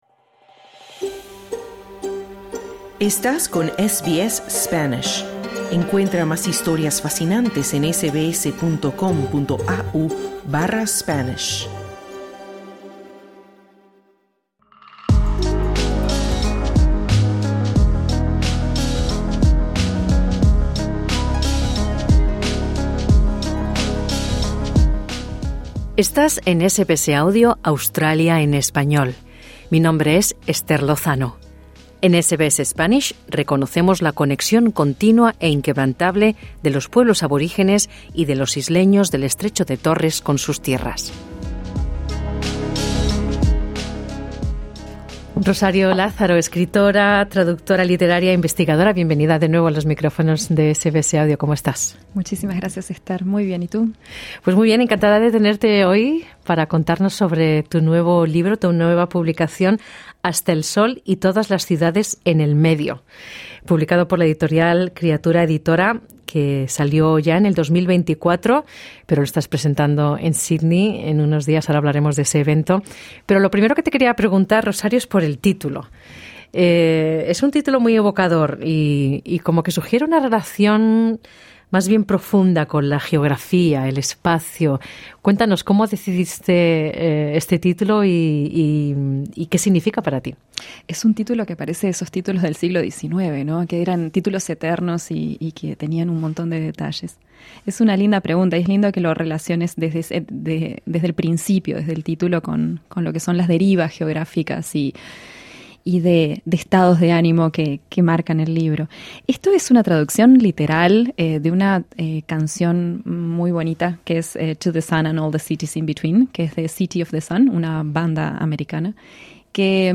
La autora visita los estudios de SBS Spanish para conversar sobre su obra.